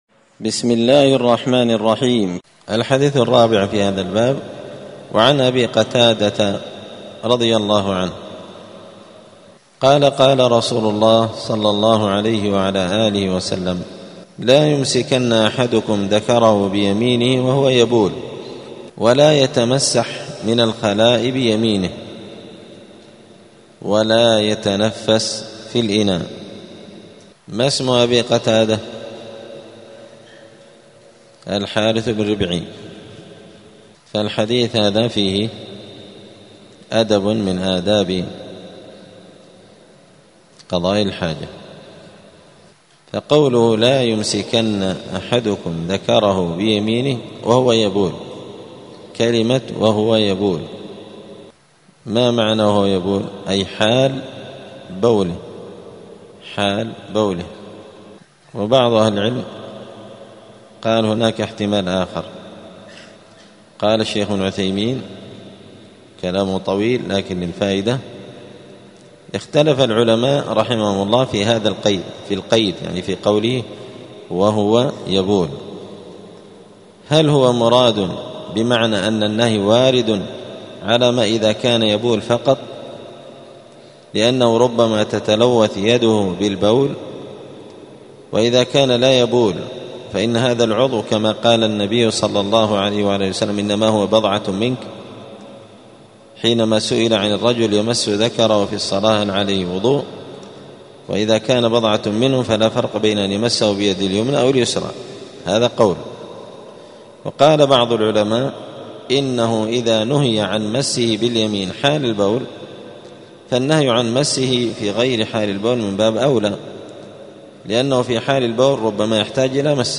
دار الحديث السلفية بمسجد الفرقان قشن المهرة اليمن
*الدرس الرابع والستون [64] {باب الاستطابة حكم الاستنجاء باليمين}*